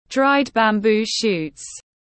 Măng khô tiếng anh gọi là dried bamboo shoots, phiên âm tiếng anh đọc là /draɪd bæmˈbuː ʃuːt/
Dried bamboo shoots /draɪd bæmˈbuː ʃuːt/